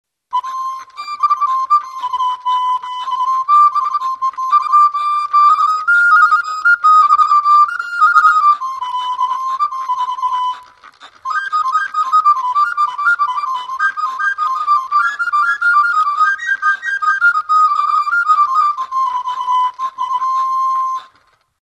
Натуральный звук 1763 года,  не современная игра, пусть даже и на аутентичных инструментах по старинным нотам.
Перед вами "сиринетт", маленький механический органчик фирмы "Gavot", на два регистра по десять труб каждый.
Носителем музыки служит деревянный валик с выдавленными точками.
Валик вмешал десять мелодий, последнюю, самую сложную и быструю, под названием "Les Folies d'Espagne" предлагаю послушать.К сожалению, кроме даты выпуска и имени мастера (Gavot fils) на валике больше ничего нет.Может это народная мелодия? а может импровизация на коленке?
mp3_anonymous_serinette.mp3